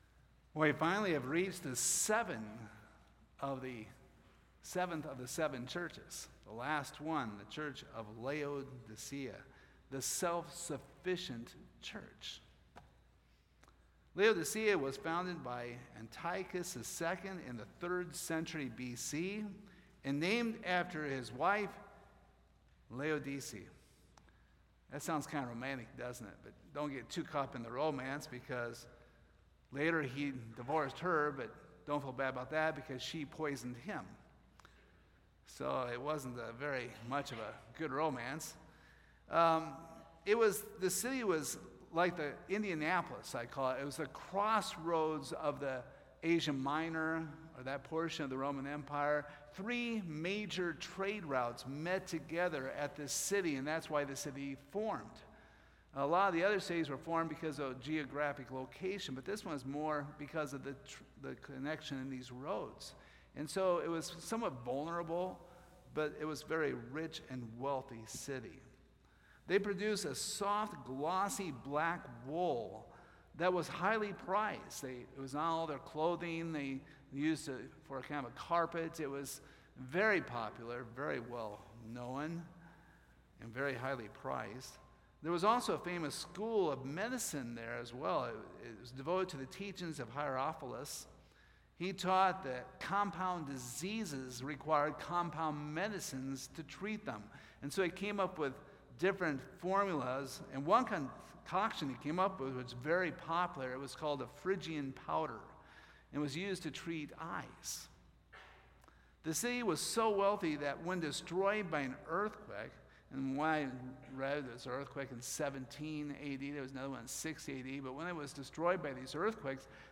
Service Type: Sunday Morning What does it mean when it says that the Laodicean Church is "lukewarm"?